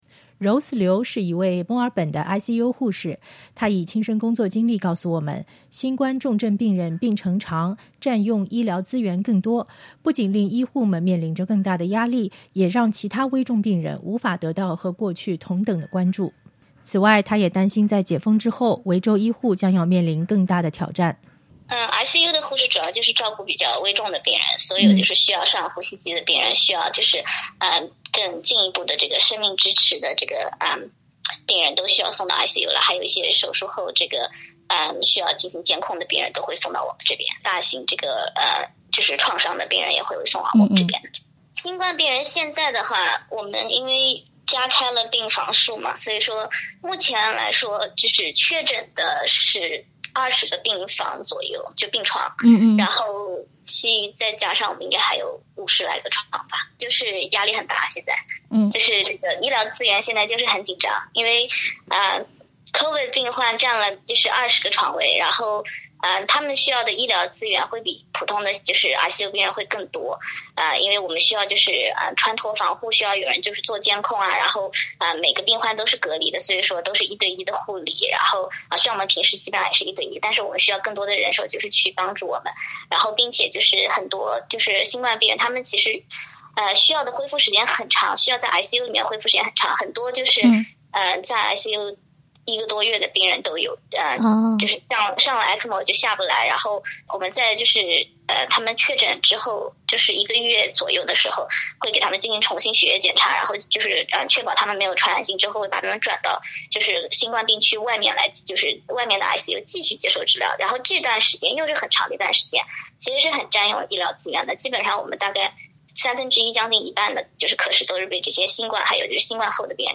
注：应受访者要求，采访声音经过特别处理。